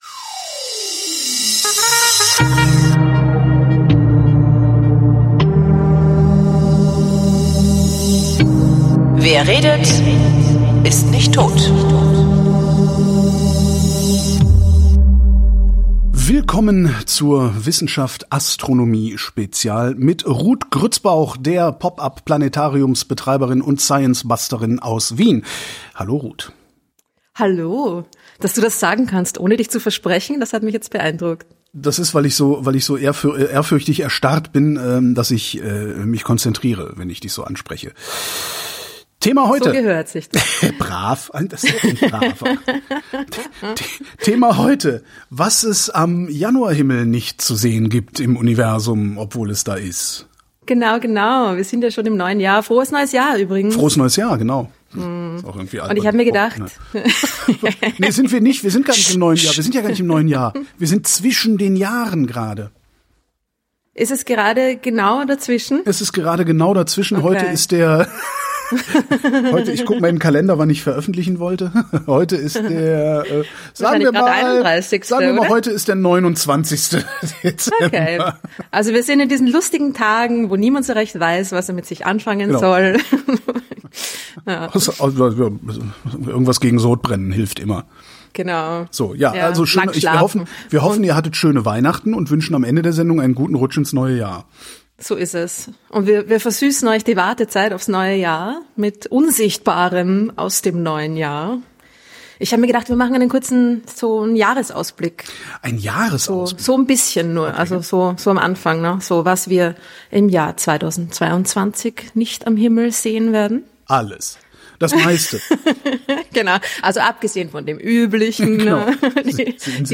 wrint: gespräche zum runterladen